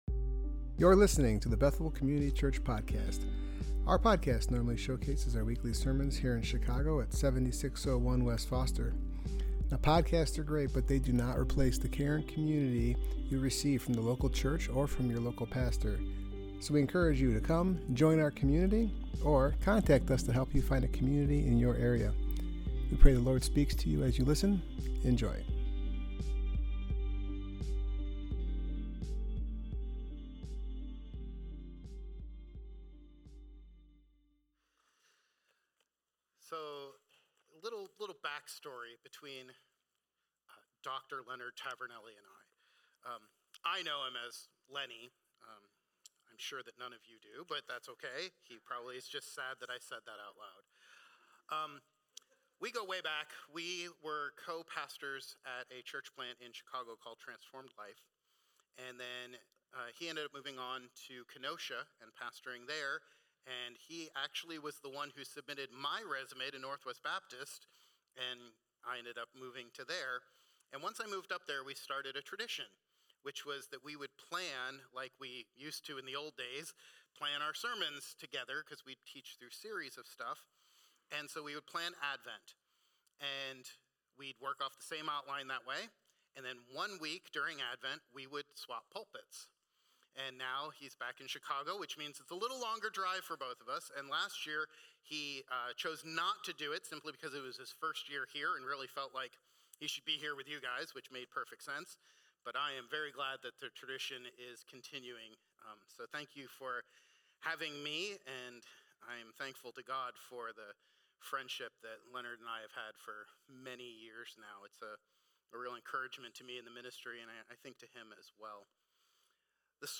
Passage: Luke 2:8-14 Service Type: Worship Gathering Topics